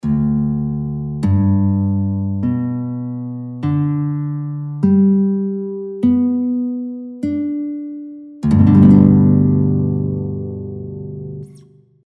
Семиструнна гітара або кобза – академічна
Акорд – ре-соль-сі-ре-соль-сі-ре, DGBDGBD (mp3):
Hitara_7-str_G-strij_AKAD_Akord_DGBDGBD.mp3